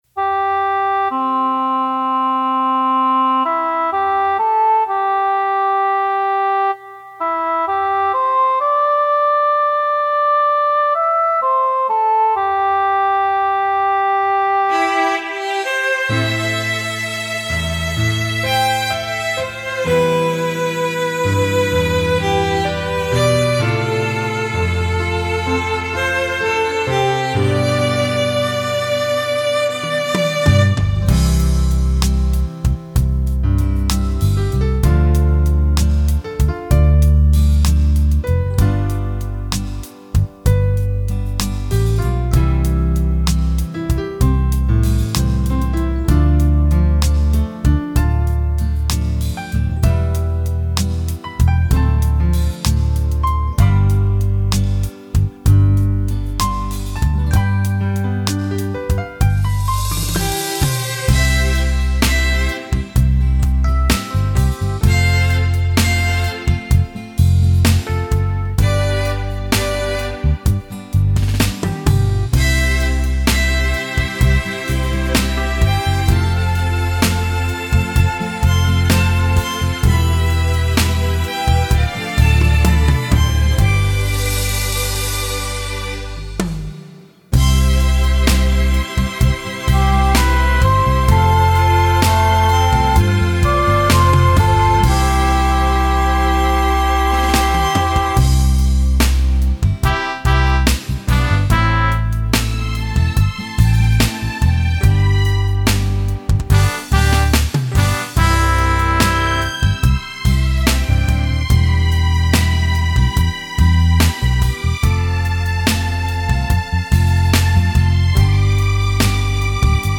Nghe nhạc hòa tấu karaoke: